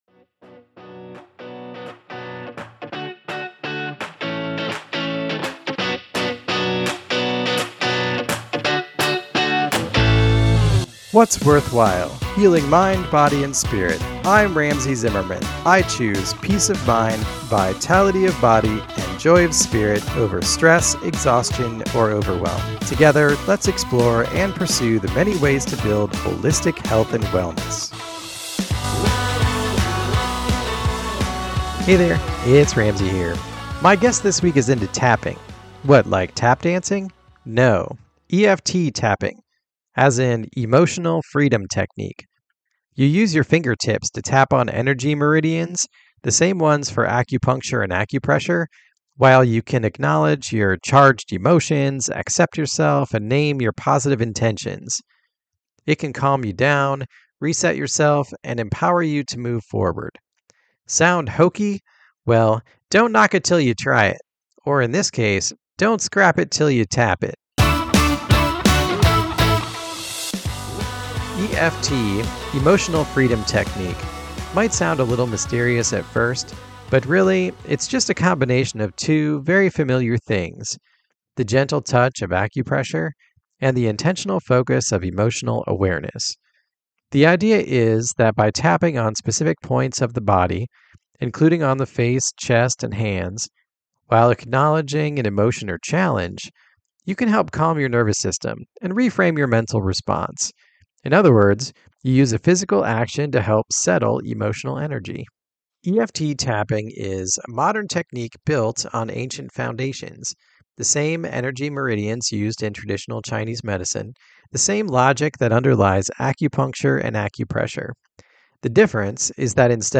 My guest this week is an EFT Tapping practitioner, and she leads a demonstration during our episode, but in this solo message, I give a deeper explanation of what the technique is, how it works, and my first impressions as I learn about it.